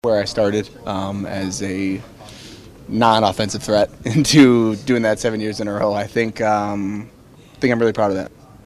Rust says it’s been a journey.